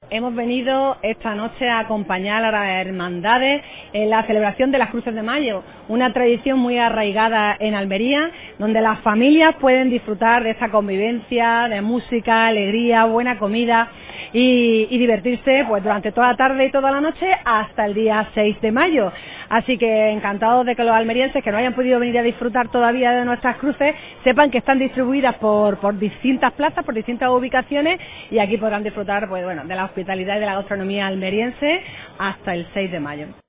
Alcaldesa de Almería, María del Mar Vázquez